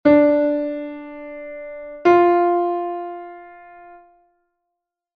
re_fa_tercera.mp3